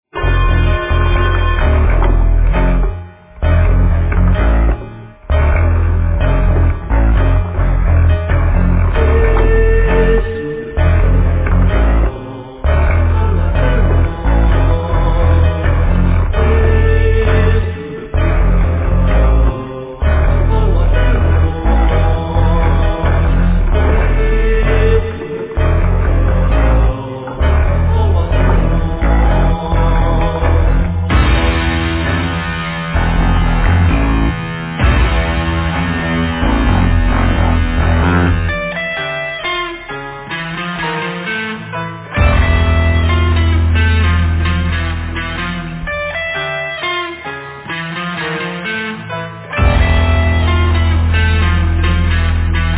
composition, vocals, direction, guitar
drums, percussion, vocals
piano, mellotron, vocals
bass
Cello